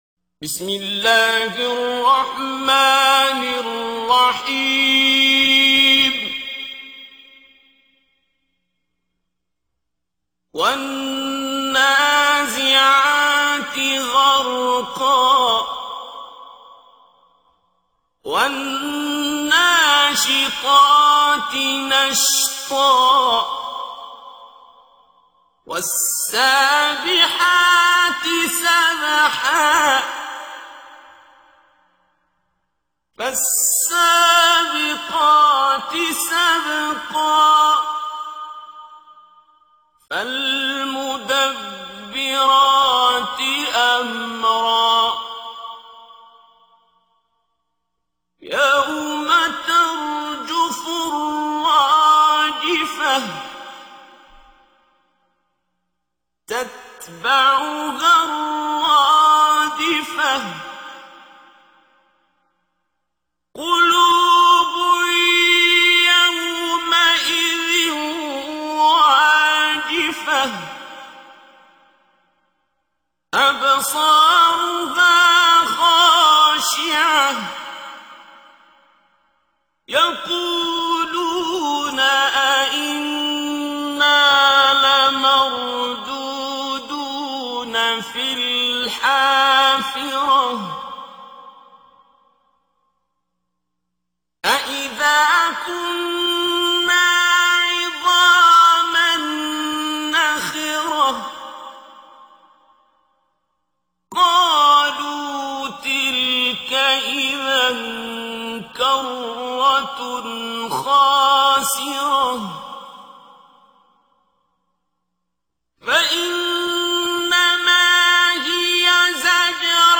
سوره ای که با خواندنش در قبر و قیامت تنها نخواهید شد + متن و ترجمه +تلاوت استاد عبدالباسط